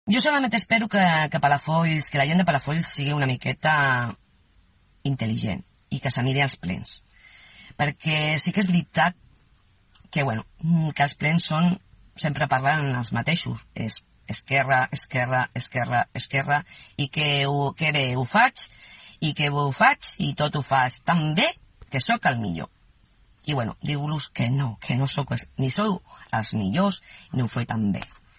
Durant el vídeo publicat per la formació, la regidora portaveu dels comuns arremet contra el govern a qui acusa de mentir i els adverteix que presentaran les mocions que creguin convenient i que continuaran fent oposició constructiva per molt que, segons la regidora, el govern acusi a l’oposició d’oportunista o d’aprofitar les circumstàncies per atacar els partits de l’oposició.